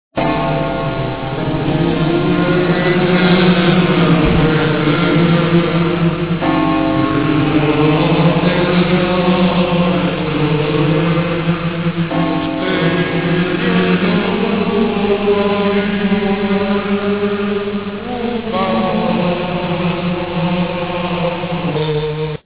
The musical background of the Home Page of the site is my own record of Saint Ubaldo's hymn, which is performed at Gubbio during the Mass of every May 15th.
CANTO.WAV